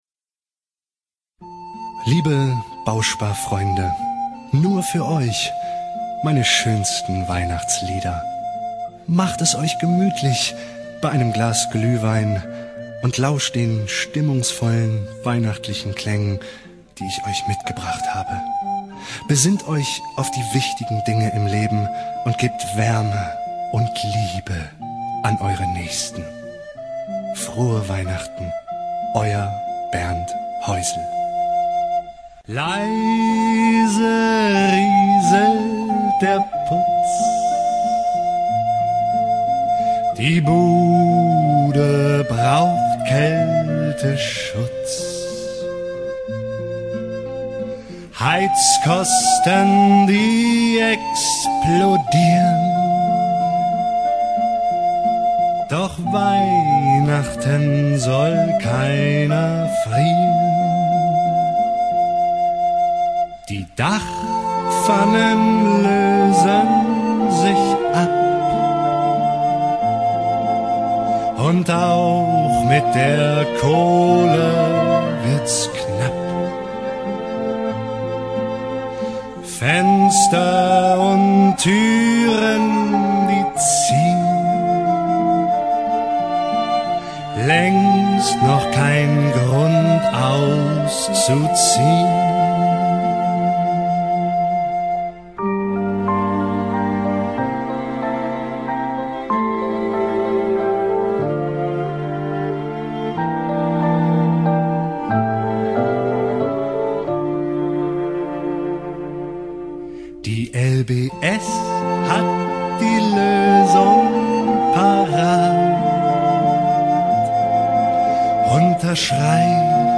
Frische, dynamische, flexible und markante Sprech- und Gesangsstimme, optimal für Werbung, Voice Over, Synchron, Dokumentationen, Hörbücher und Hörspiele.
Sprechprobe: Werbung (Muttersprache):
My voice is fresh, dynamic, warm, flexible and striking.